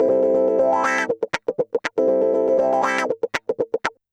Index of /90_sSampleCDs/USB Soundscan vol.04 - Electric & Acoustic Guitar Loops [AKAI] 1CD/Partition C/04-120GROWAH